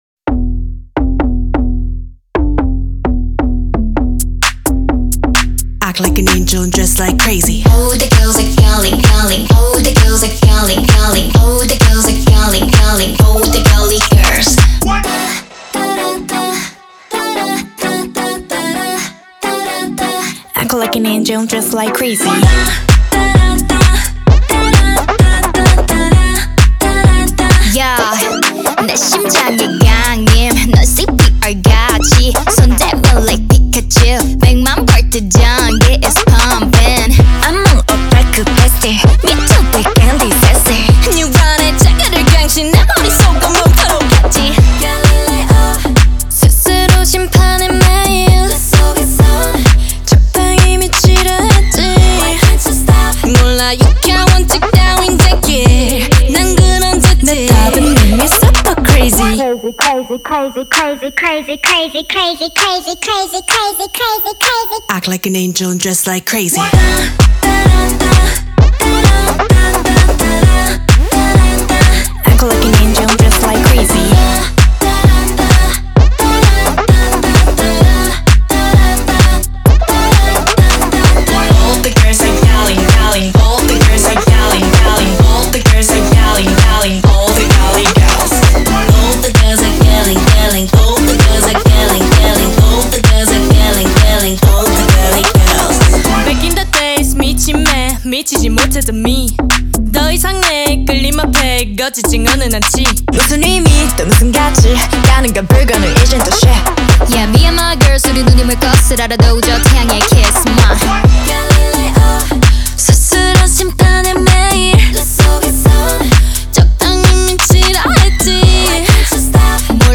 BPM130-130
Audio QualityPerfect (High Quality)
K-Pop song for StepMania, ITGmania, Project Outfox
Full Length Song (not arcade length cut)